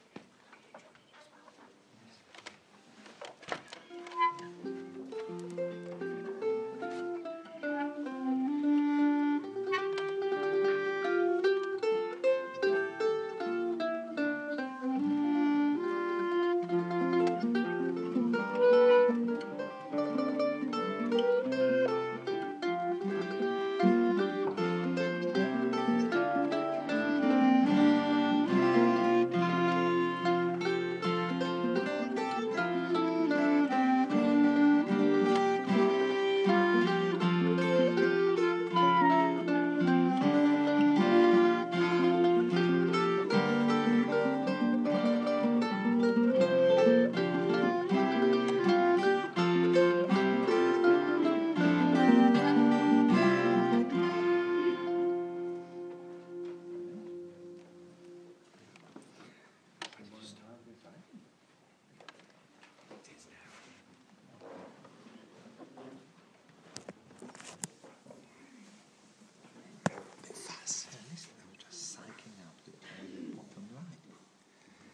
Instrumental
Instrumental with new clarinetist